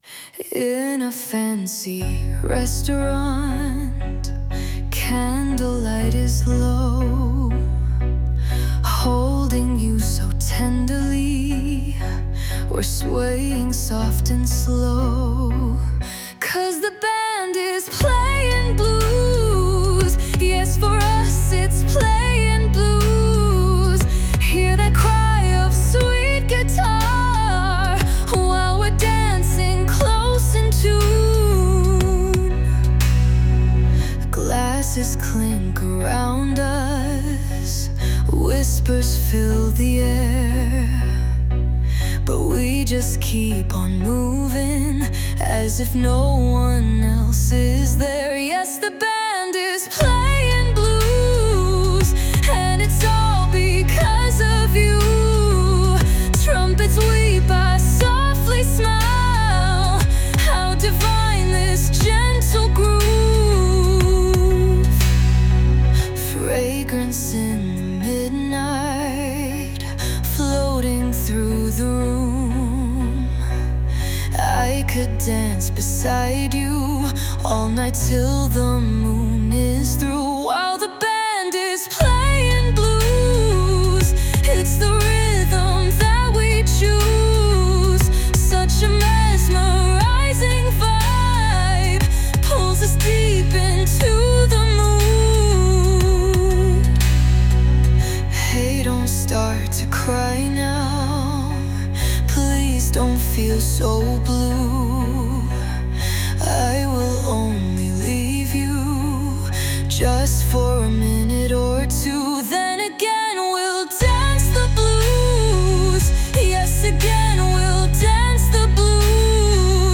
English, Romantic, Psychedelic, Trance | 15.04.2025 18:04